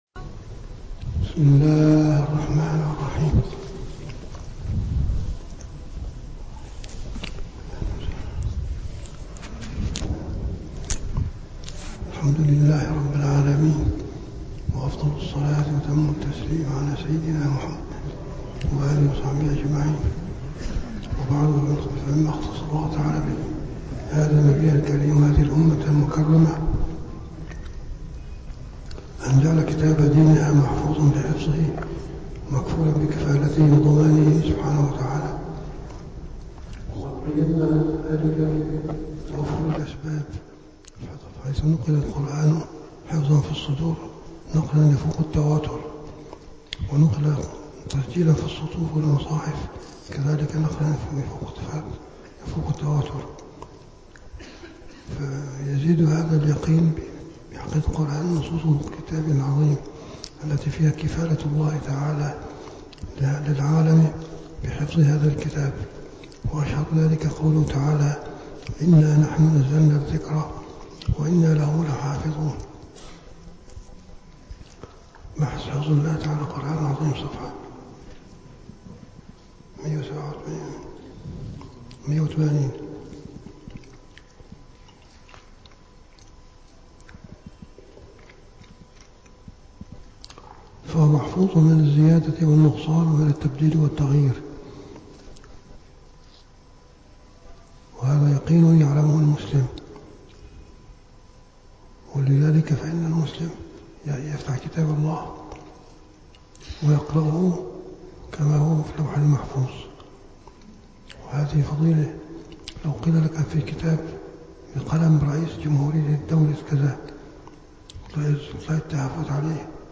- الدروس العلمية - دورة مختصرة في علوم القرآن الكريم - 10- علوم القرآن الكريم